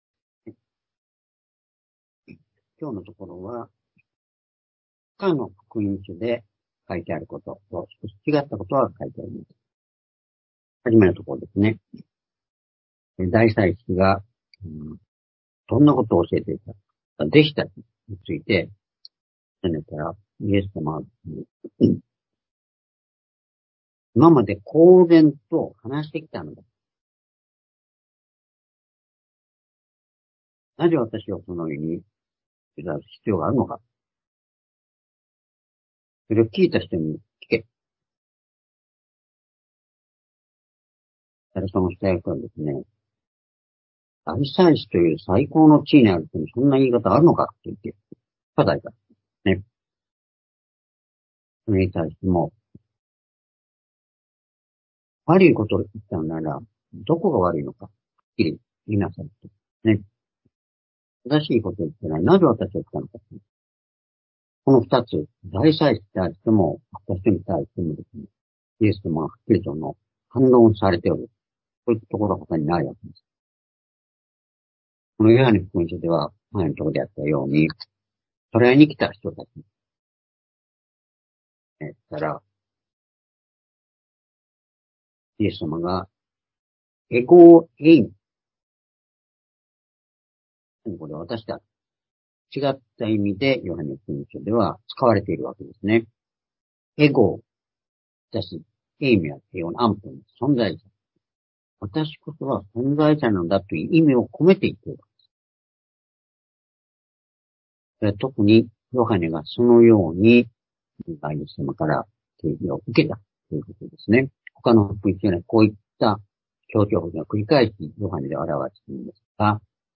「ペテロの涙、キリストのまなざし」-ヨハネ１８章１９節～２７節-２０２４年10月２７日（主日礼拝）